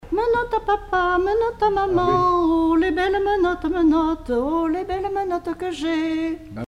formulette enfantine : jeu des doigts
Collectif-veillée (1ère prise de son)
Pièce musicale inédite